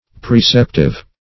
Preceptive \Pre*cep"tive\, a. [L. praeceptivus.]